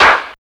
150UKCLAP1-L.wav